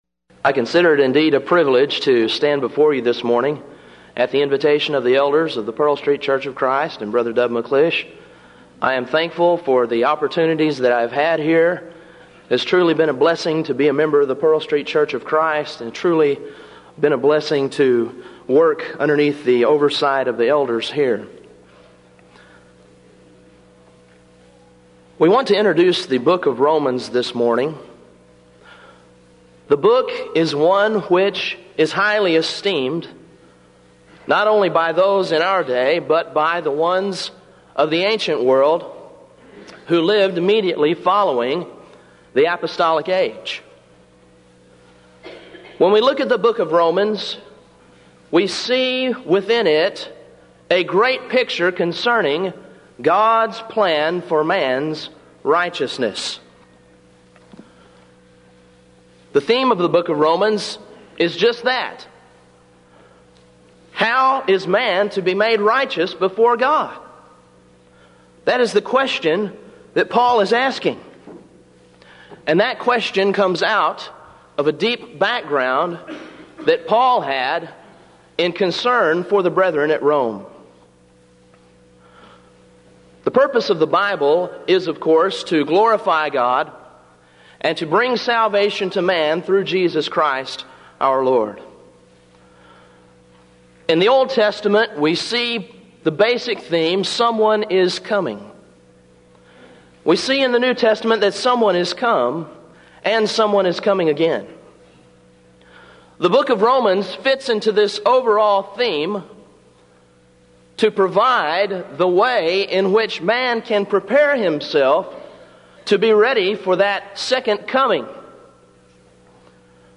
Event: 1996 Denton Lectures Theme/Title: Studies In The Book Of Romans